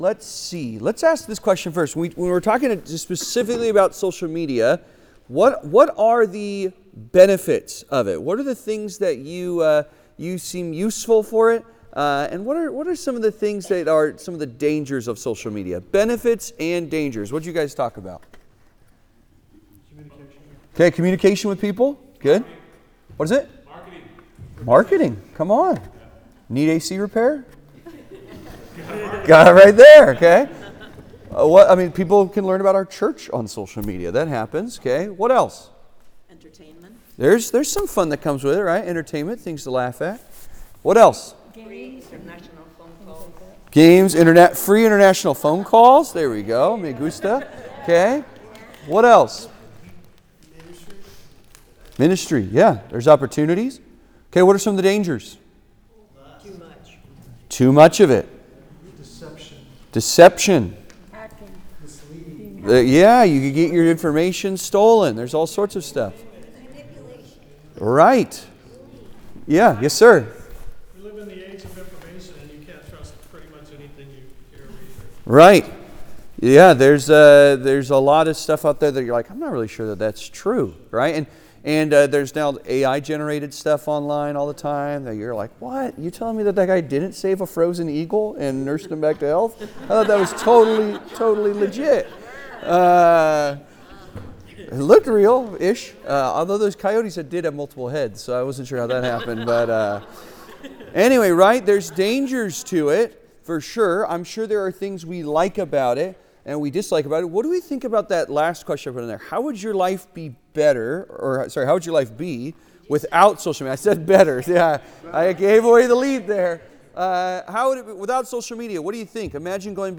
What's Trending - The Scriptures and Your Social Media (Sermon) - Compass Bible Church Long Beach